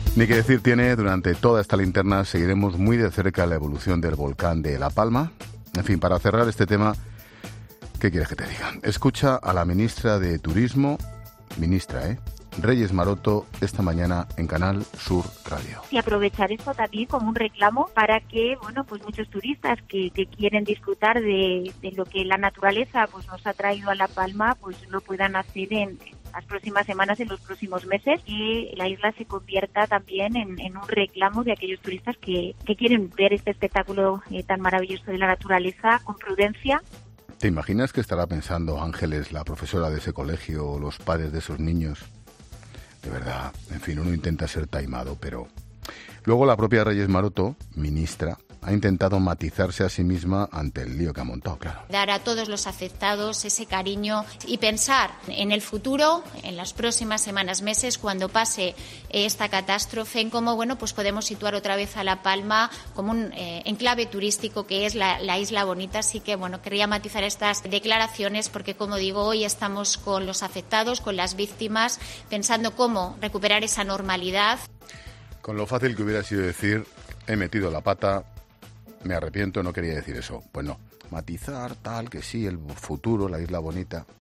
El director de 'La Linterna' ha hecho referencia a las últimas declaraciones de la ministra de Turismo sobre el volcán en erupción